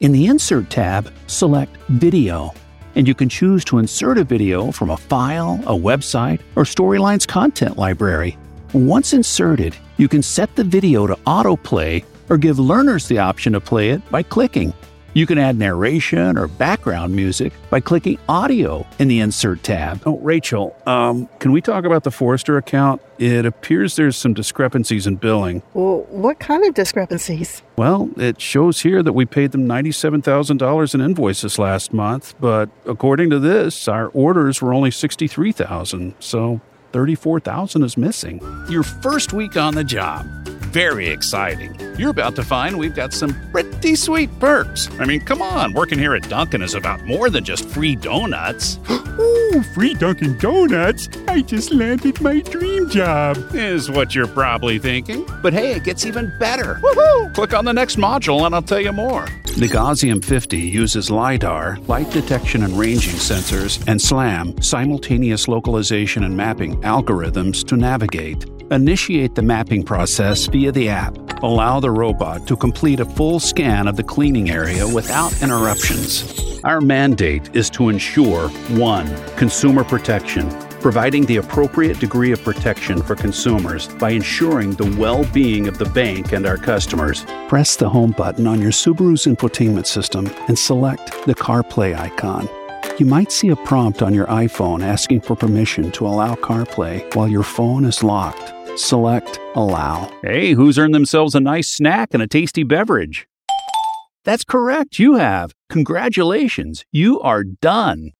Englisch (Amerikanisch)
Natürlich, Unverwechselbar, Vielseitig, Freundlich, Warm
E-learning